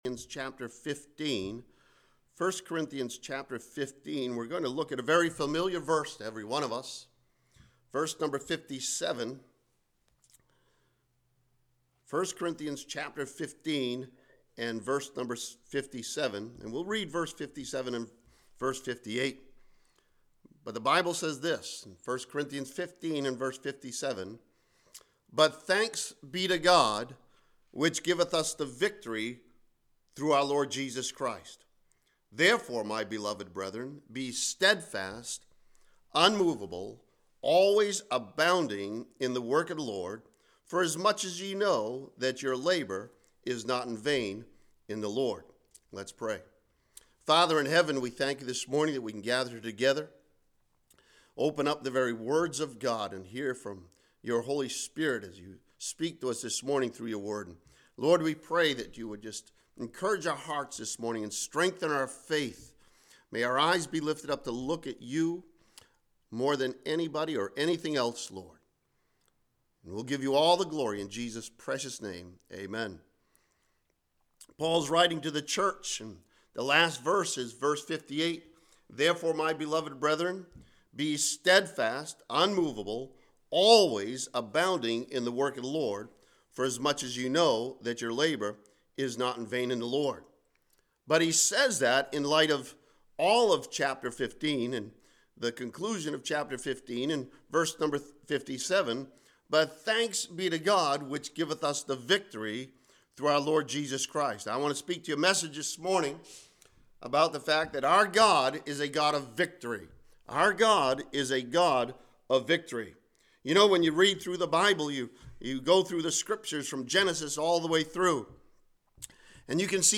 This sermon from 1 Corinthians chapter 15 encourages believers with the truth that our God is a God of victory!